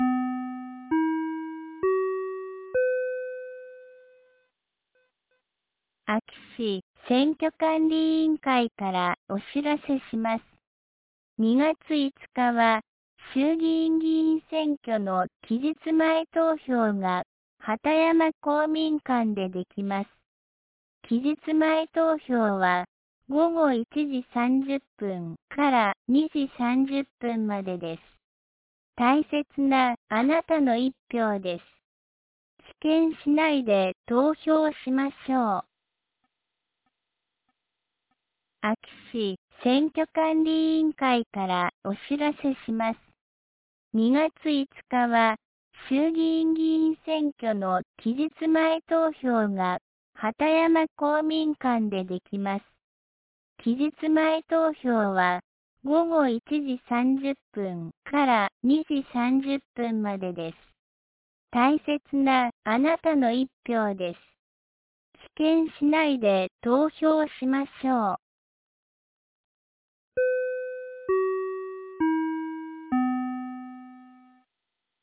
2026年02月04日 09時05分に、安芸市より畑山へ放送がありました。